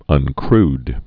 (ŭn-krd)